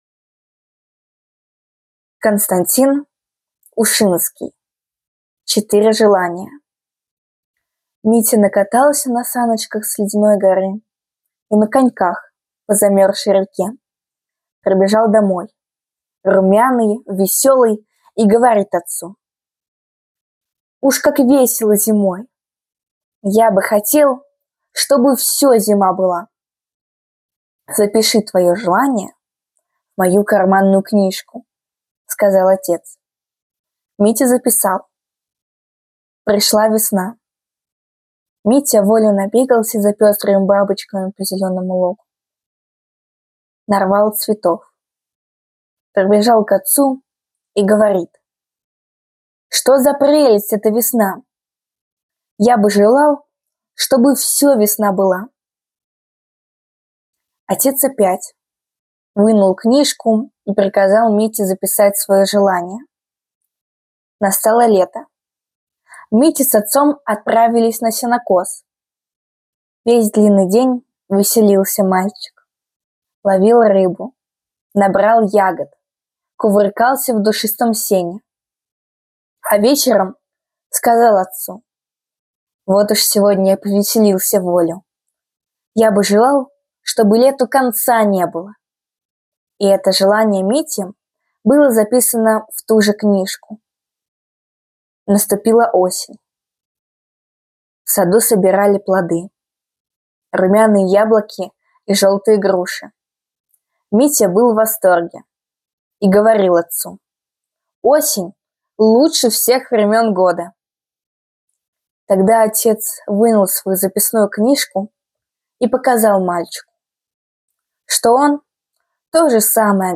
Сегодня у нас для вас аудиосюрприз — рассказ Константина Ушинского «Четыре желания»! В нём мальчик Митя рассуждает, какое время года лучше, и понимает, что каждое из них по-своему прекрасно.